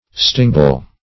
Stingbull \Sting"bull`\, n. (Zool.)